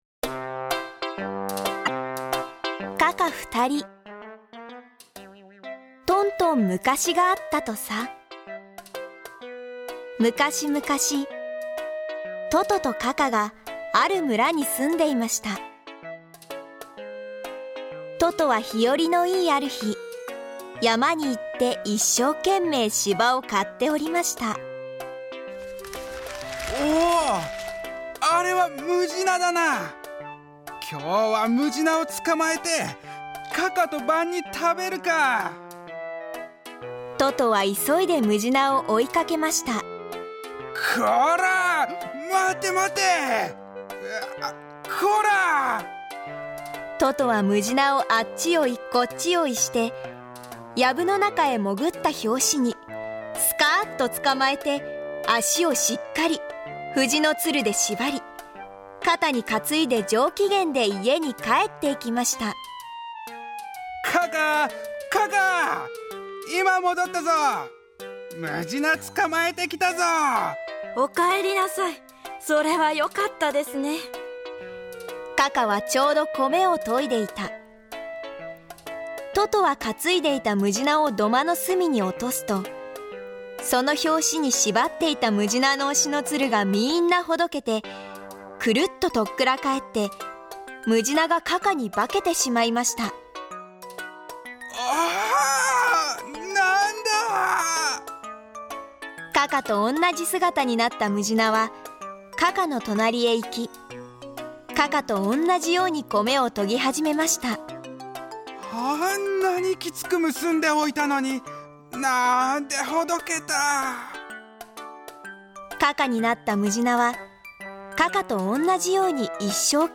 ゆかいなお話「カカふたり」は、テンポもよくスムーズに録音も終了。 青年部の息のあった朗読をお楽しみください。 出演者：劇団ひまわり新潟エクステンションスタジオ